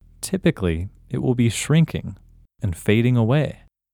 OUT – English Male 14